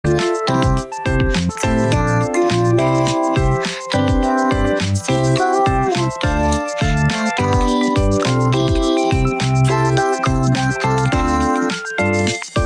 Sounds like a vocaloid cover of a song.